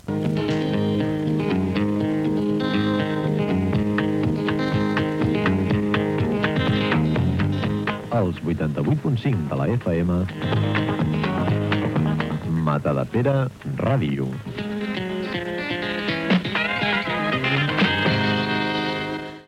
Indicatiu de l'emissora i freqüència